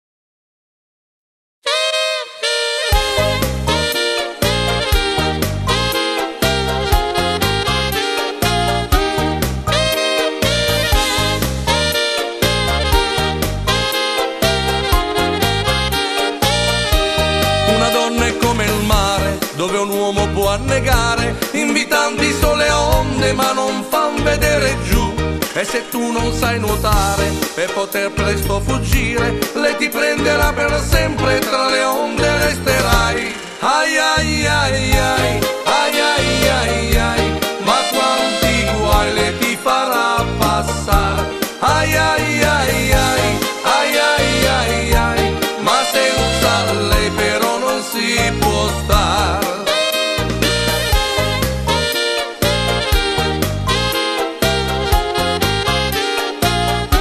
Mod. Canzone
13 brani per orchestra.